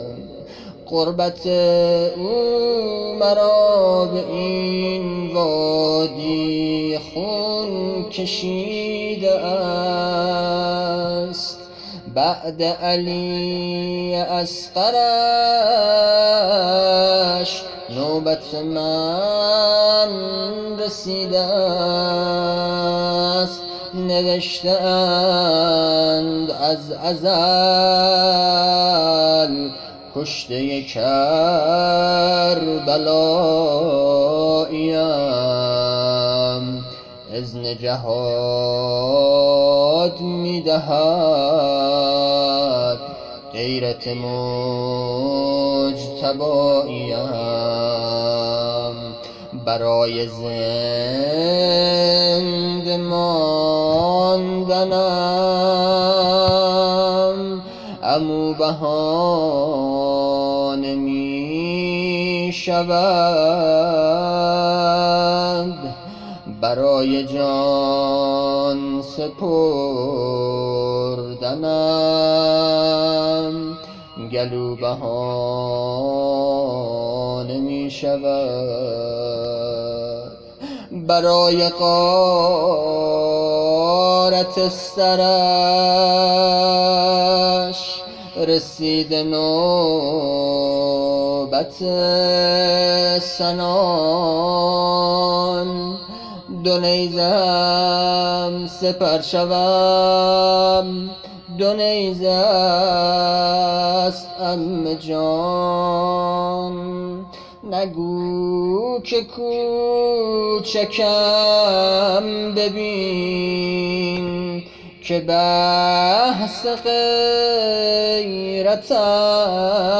روضه
محرم 1400